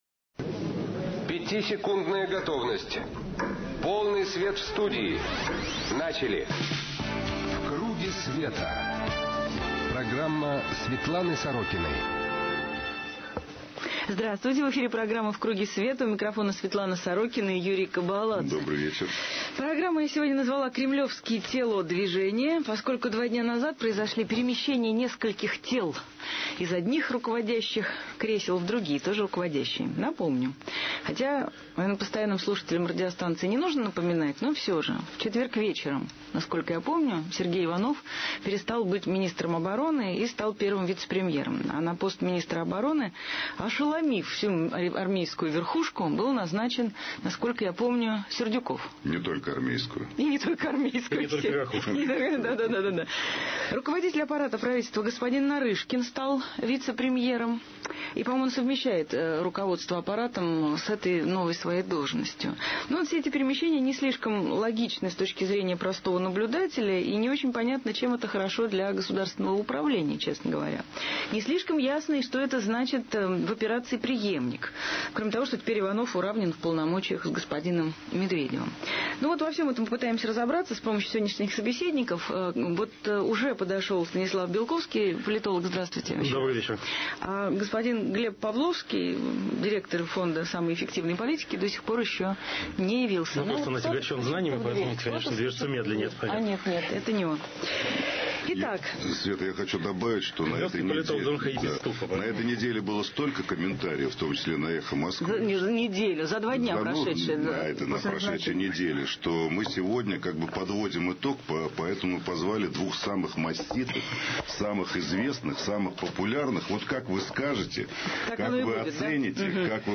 В КРУГЕ СВЕТА программа Светланы Сорокиной на радио «Эхо Москвы» соведущий - Юрий Кобаладзе 17 февраля 2007 г. Кремлёвские тело-движения . Гости - Глеб Павловский , Станислав Белковский. рубрикатор : Аудио, анонс – .mp3, 00:17, 137 Кб , эфир – .mp3, 50:04, 8 802 Кб .